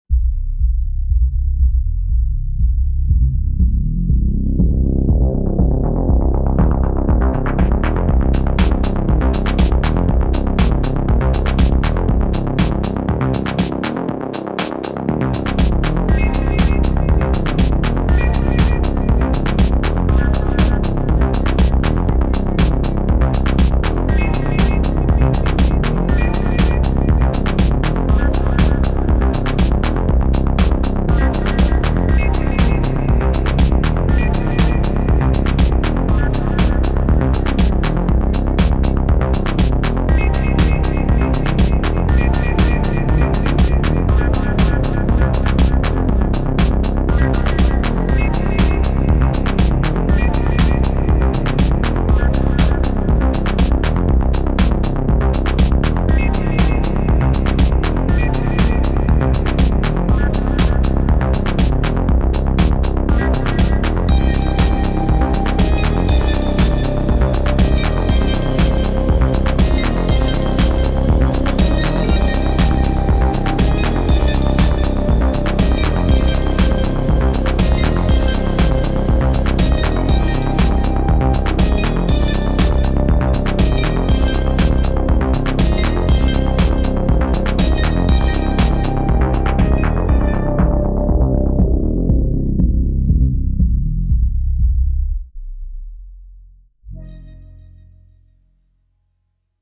Klangbeispiele: Novation Circuit Tracks
• 2 Synth-Tracks mit 6 stimmiger Polyphonie
• Reverb-, Delay- und Sidechain-Effekte
80s.mp3